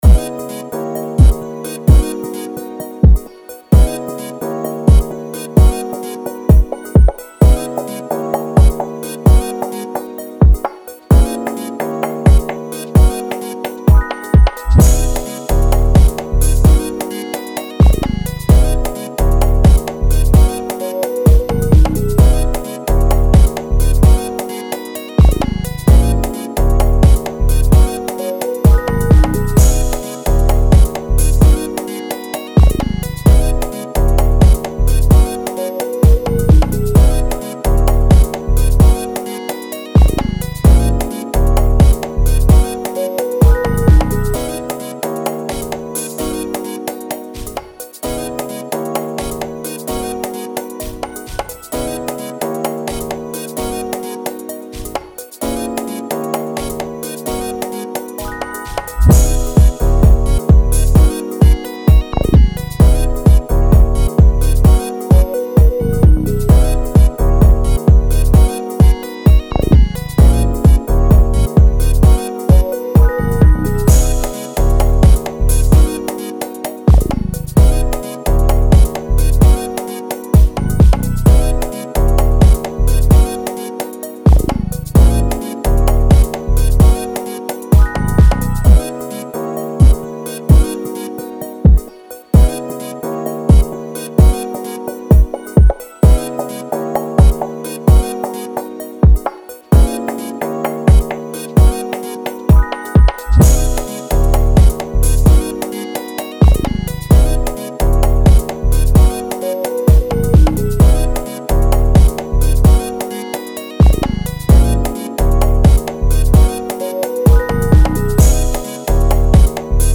BPM:130
Key: G# minor